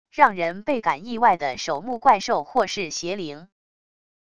让人备感意外的守墓怪兽或是邪灵wav音频